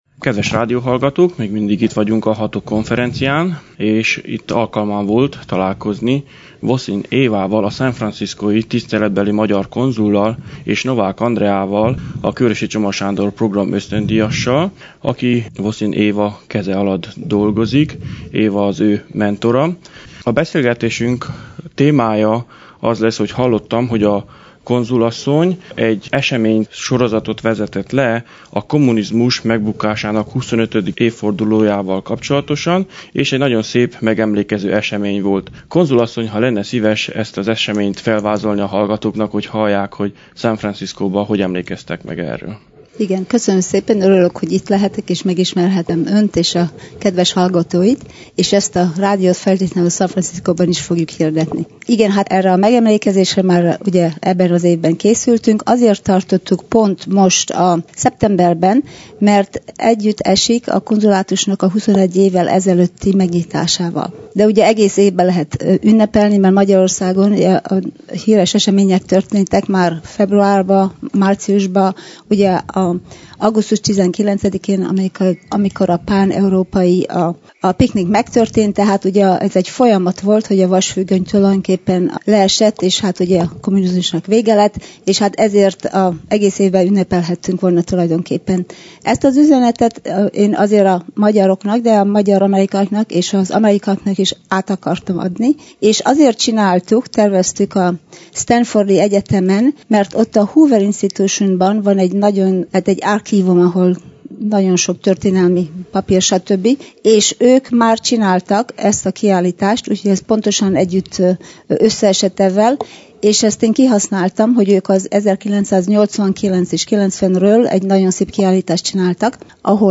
Beszélgetés
A 8. HATOG konferencián lehetőségem adódott